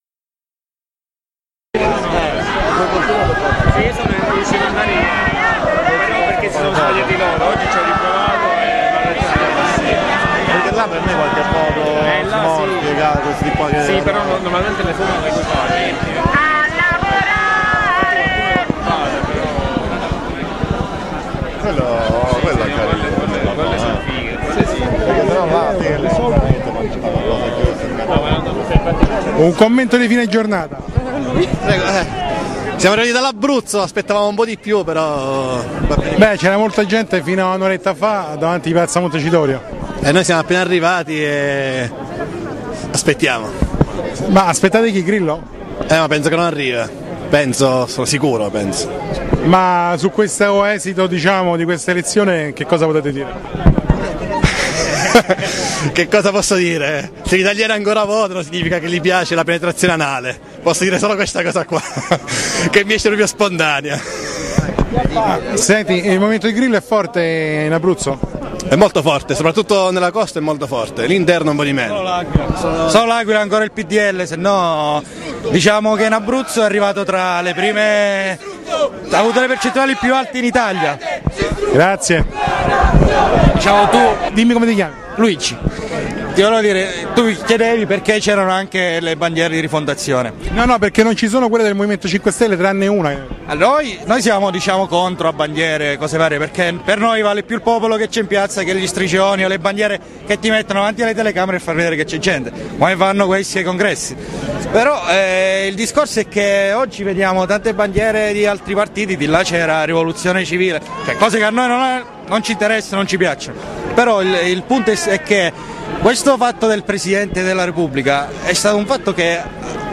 Voci della tarda sera di militanti provenienti dall'Abruzzo e ... di nuovo il Deputato Roberto Fico del MoVimento 5 Stelle.
Interviste realizzate a Roma - piazza Colonna, durante le votazioni per eleggere il nuovo Presidente della Repubblica Italiana, 20 aprile 2013.